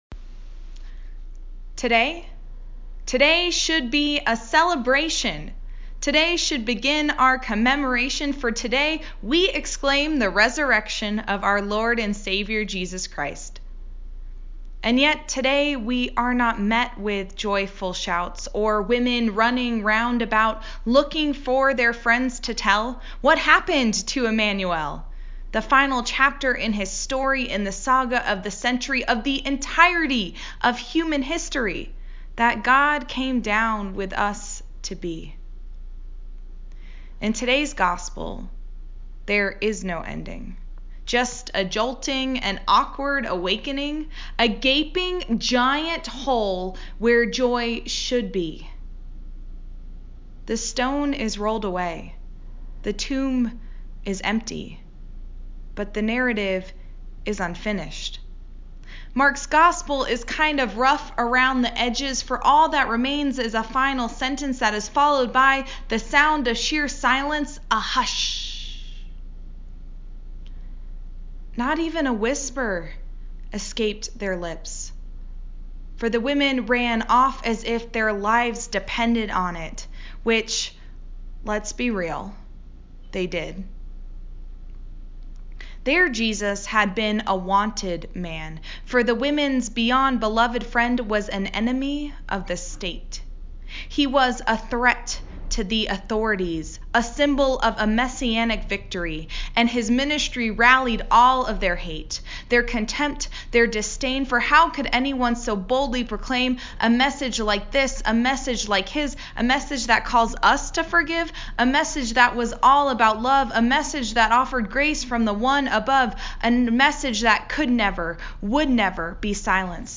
An Easter Spoken-word Sermon